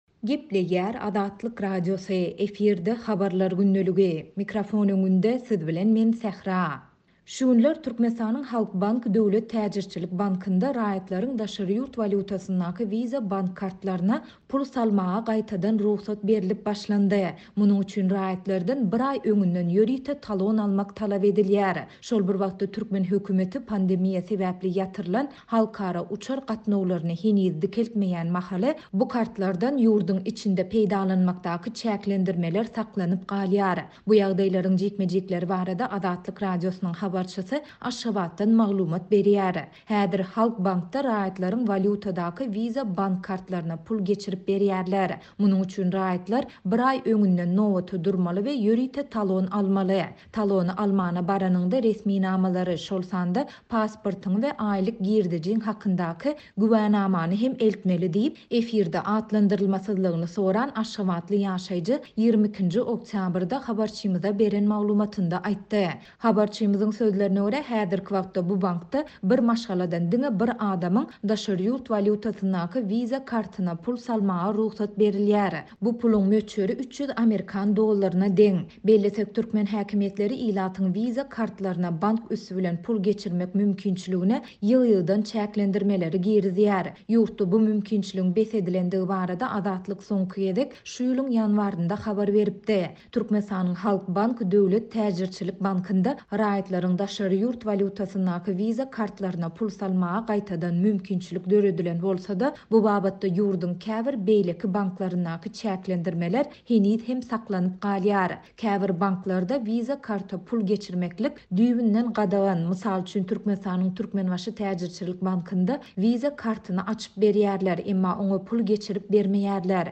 Bu ýagdaýlaryň jikme-jikleri barada Azatlyk Radiosynyň habarçysy Aşgabatdan maglumat berýär.